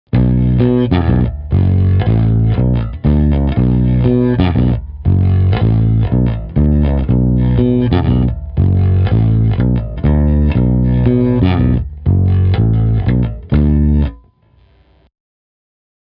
Je to pořízené přes iPad v GarageBandu přes Clean Combo.
Snímač u krku